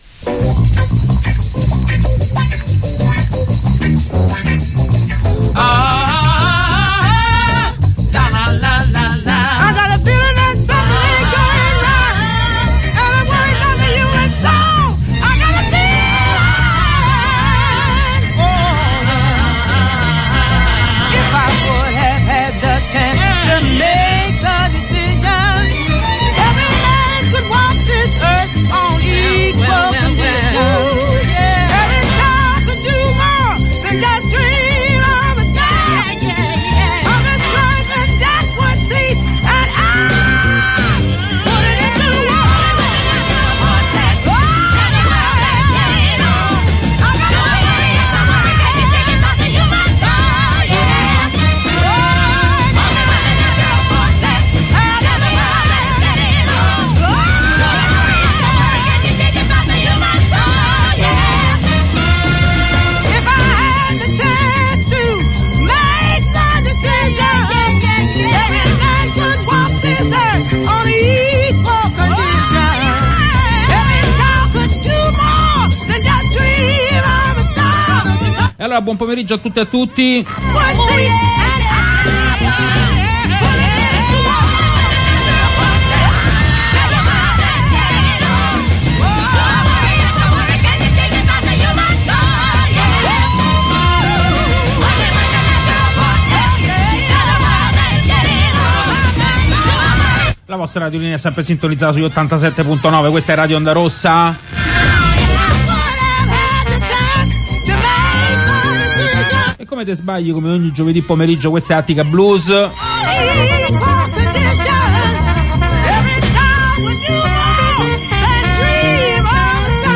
Oltre la Black Music: l'arte, le lotte, la storia e l'eredità culturale della schiavitù e della diaspora nera nel mondo. In onda ogni giovedì in diretta dalle 16 alle 17.